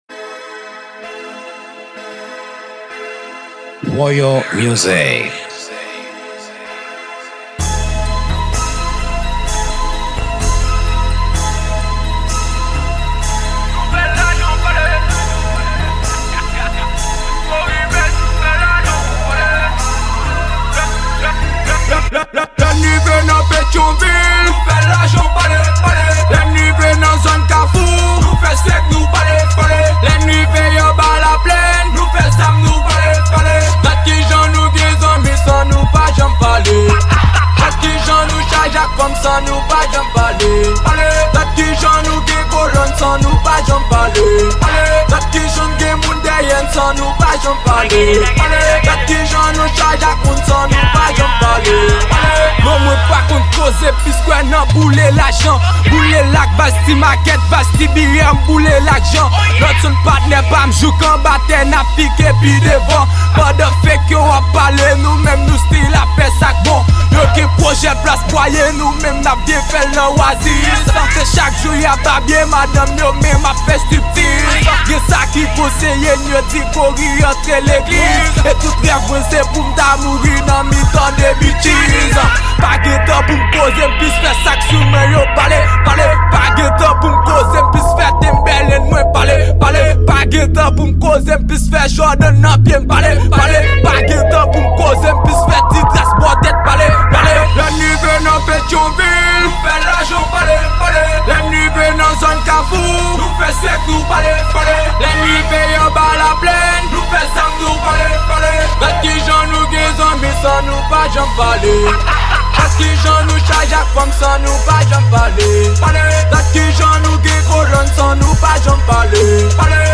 Genre: TRAP.